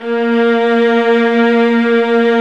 Index of /90_sSampleCDs/E-MU Producer Series Vol. 2 – More Studio Essentials/Composer/StArcoViolas Vib
A#2-R.wav